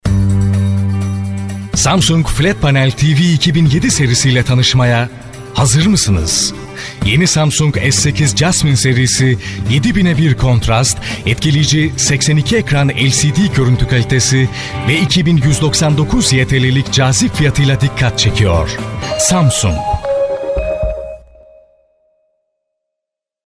Masculino
Turkish Audiobook
From medium friendly read to articulate and energetic hard-sell.